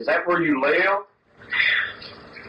EVP-1 ___